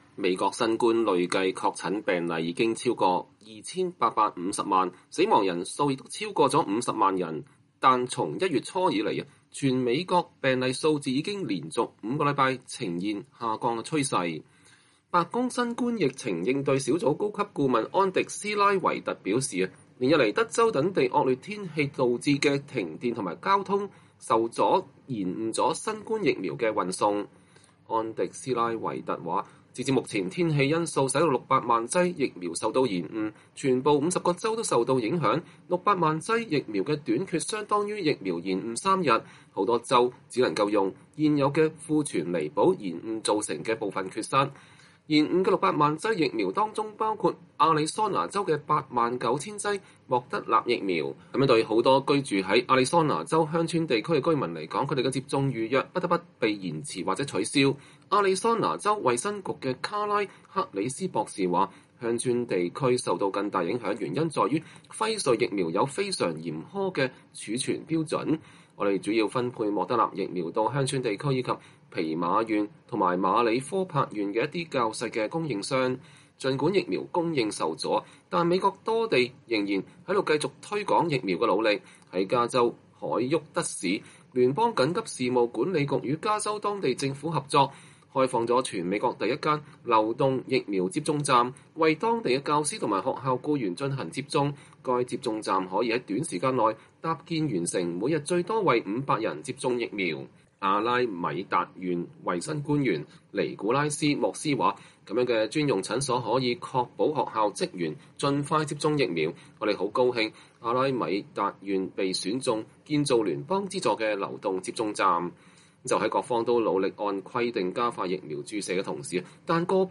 美國之音新聞報導